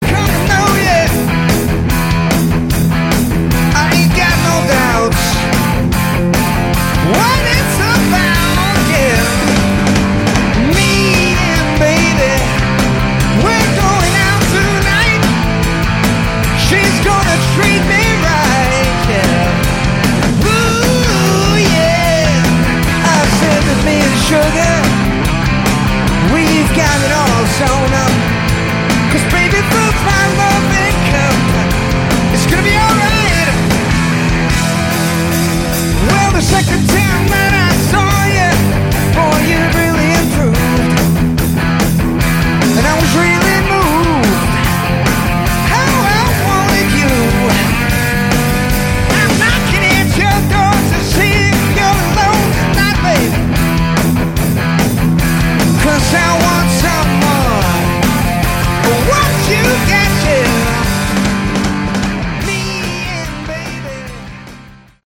Category: AOR
lead vocals
bass
lead guitar, backing vocals
drums, backing vocals